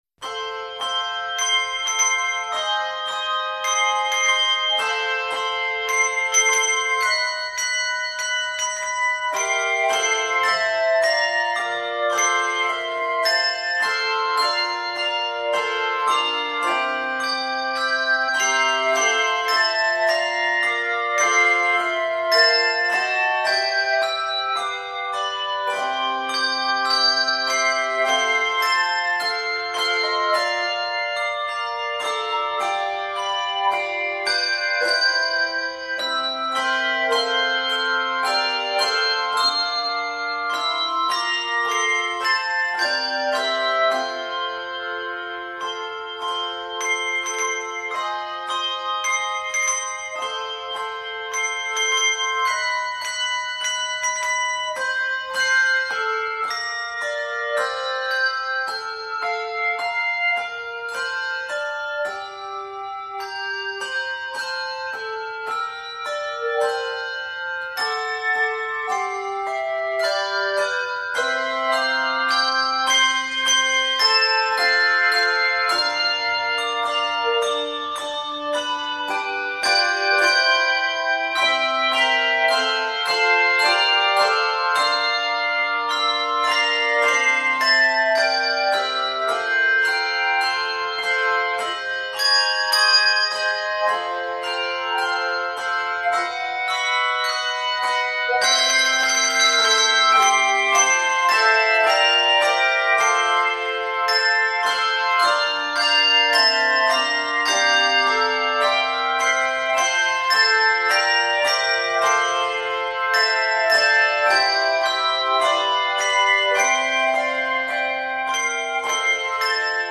with interesting and innovative juxtapositions of harmony
Key of F Major. 67 measures.